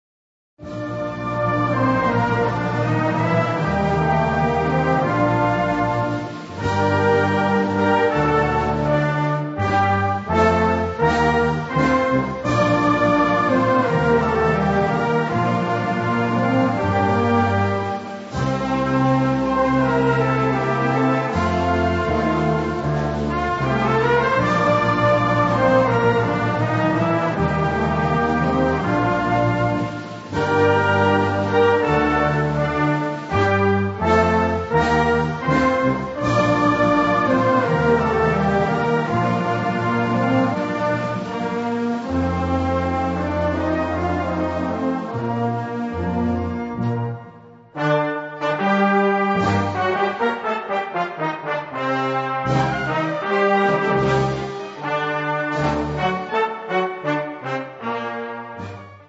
Catégorie Harmonie/Fanfare/Brass-band
Instrumentation Ha (orchestre d'harmonie)